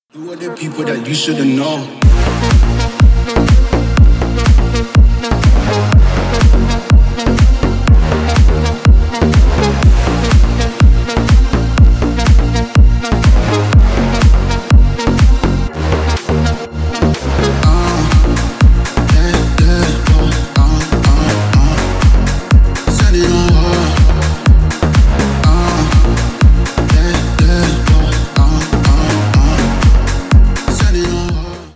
Танцевальные
клубные